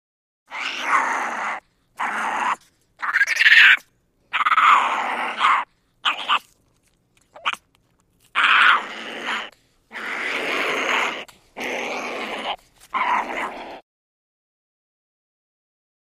Monkey ( Unknown ) Growls, Chatter. Series Of Angry Growls And Chitters. Close Perspective.